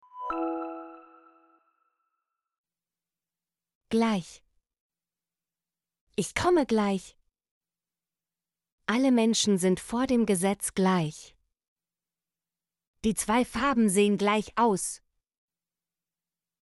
gleich - Example Sentences & Pronunciation, German Frequency List